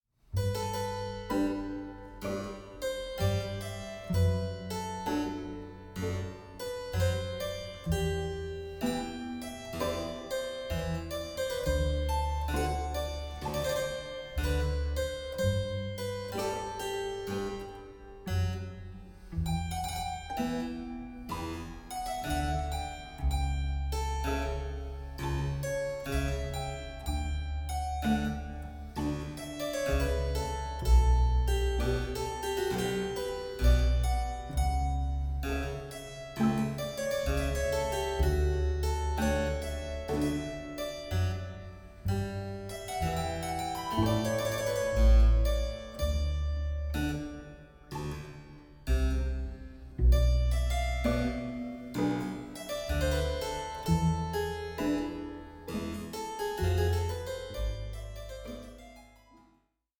(48/24, 88/24, 96/24) Stereo  14,99 Select
Largo 2:50